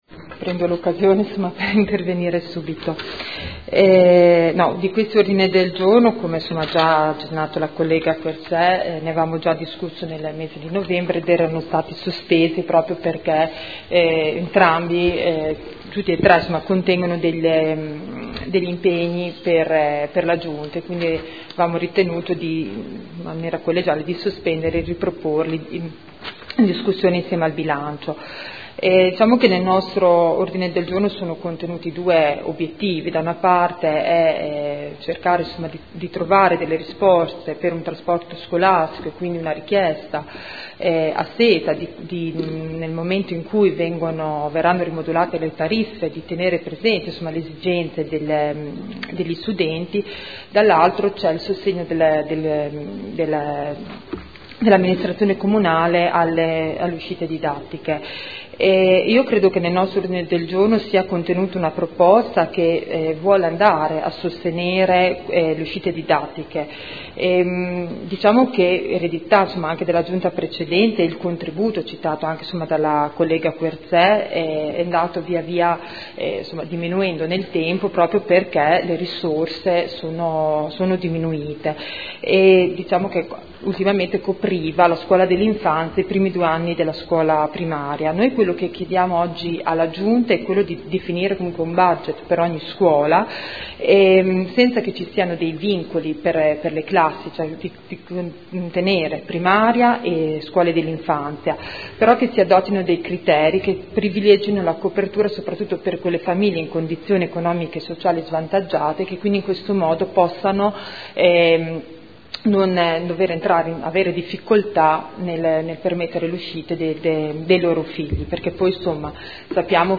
Seduta del 09/02/2015. Dibattito sugli ordini del giorno inerenti il trasporto scolastico gratuito o agevolato